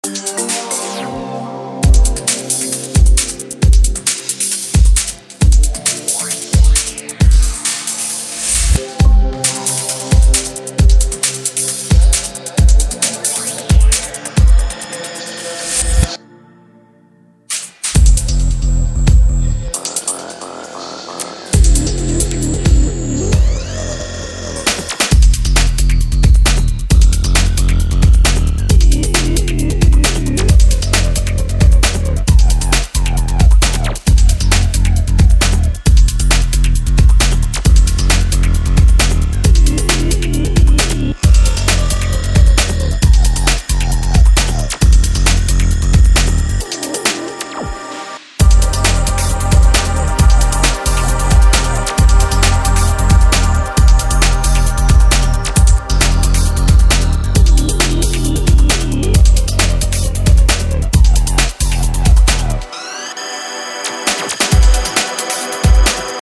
Electrofunk, Bass & Technobreaks VINYLs